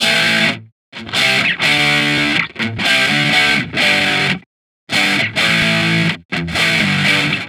Guitar Licks 130BPM (20).wav